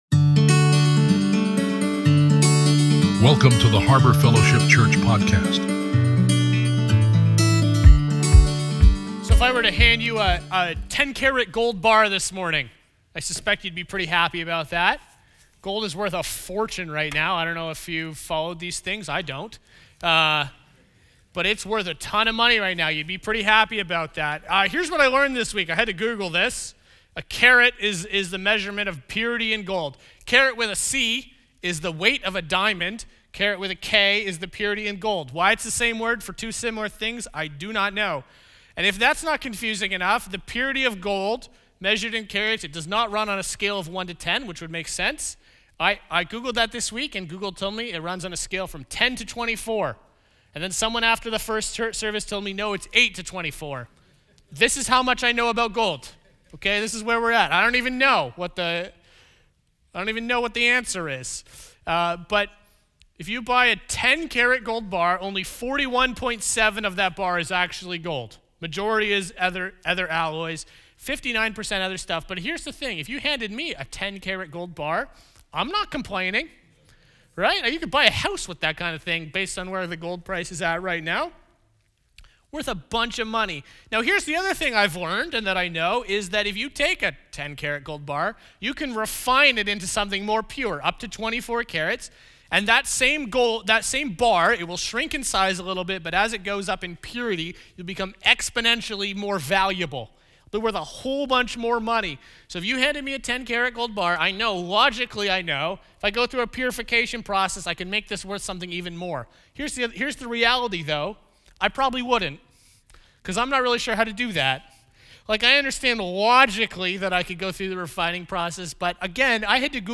Sermons - Harbour Fellowship Church